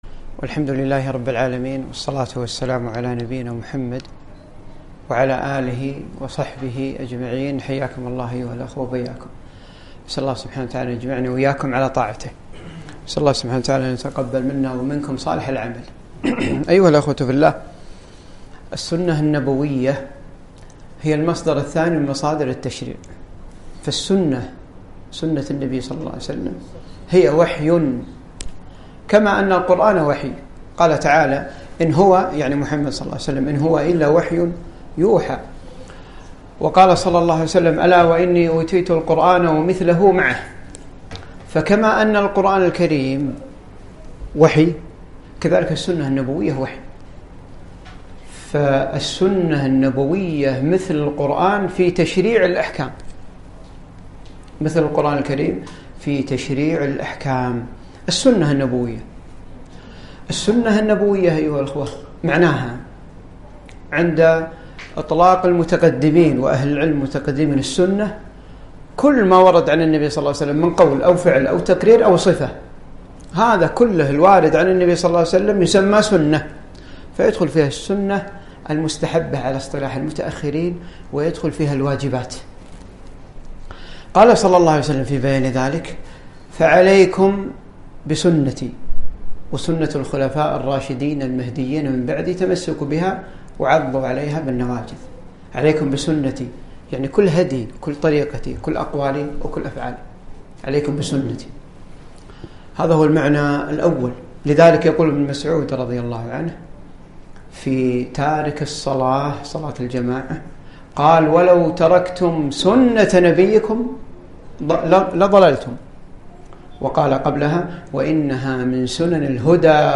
محاضرة - السنة النبوية وأثرها في واقعنا